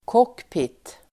Ladda ner uttalet
cockpit substantiv (engelska), cockpit [English]Uttal: [k'åk:pit] Böjningar: cockpiten, cockpitarDefinition: förarkabin i flygplan (pilot's compartment of an aircraft)